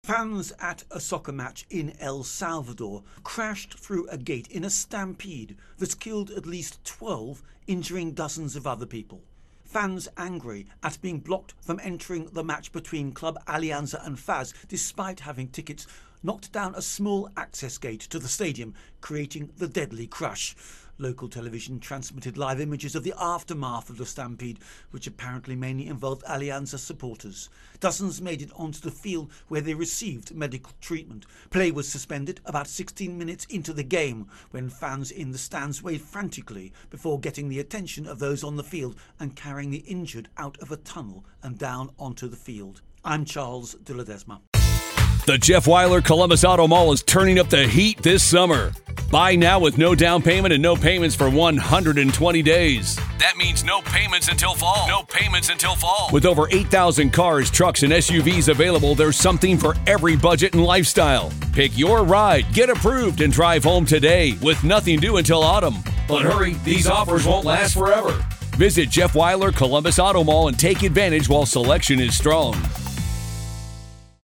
reports on El Salvador Soccer Stampede